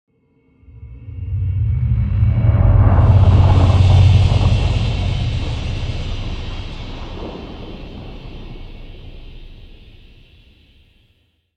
جلوه های صوتی
دانلود آهنگ رعدو برق 10 از افکت صوتی طبیعت و محیط
دانلود صدای رعدو برق 10 از ساعد نیوز با لینک مستقیم و کیفیت بالا